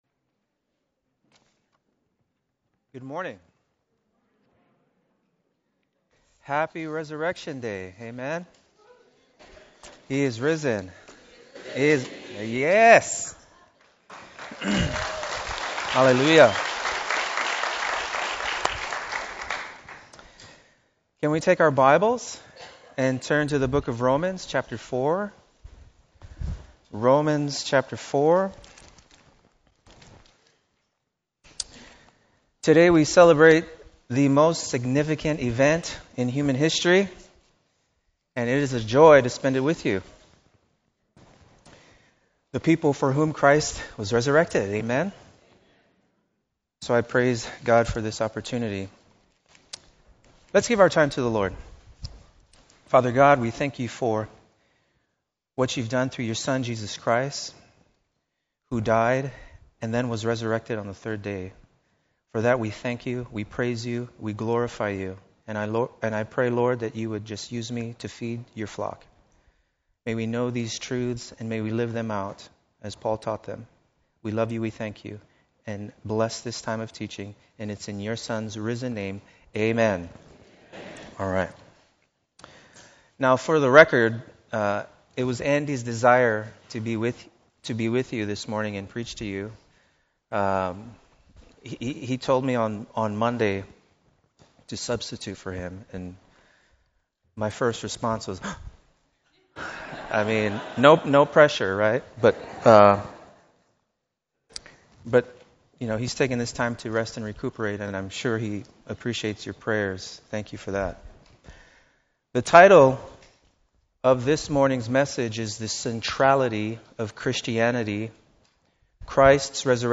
Easter Sermons